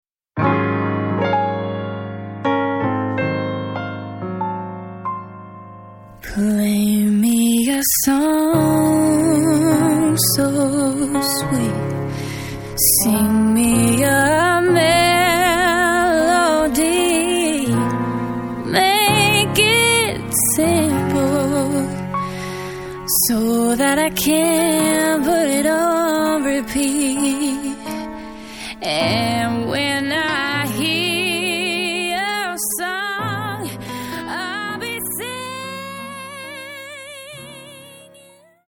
FREE SOUL